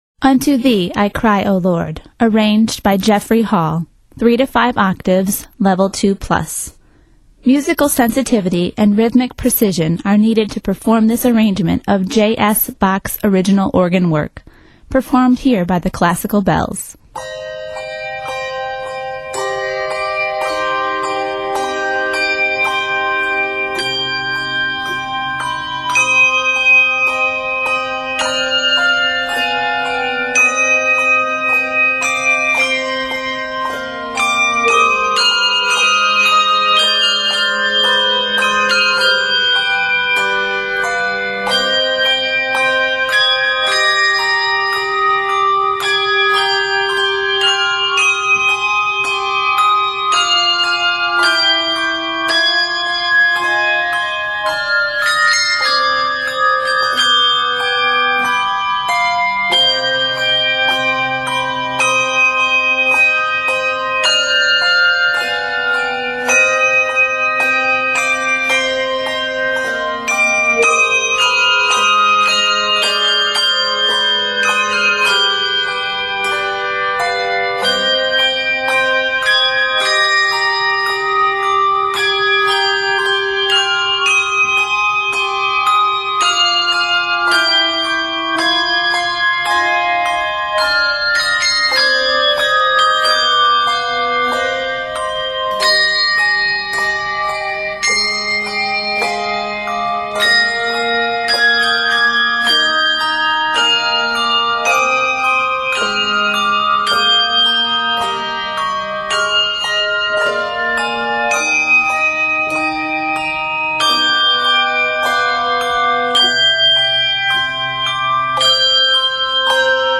handbells
Set in c minor, measures total 32.